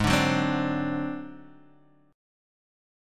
G#7b5 chord